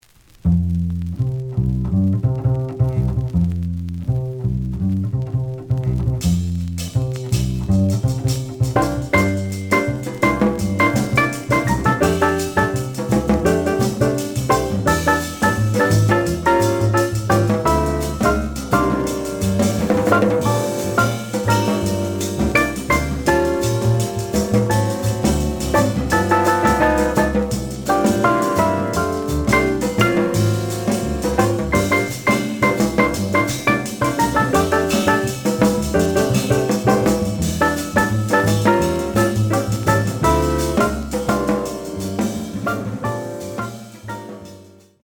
The audio sample is recorded from the actual item.
●Genre: Jazz Funk / Soul Jazz
Slight noise on both sides.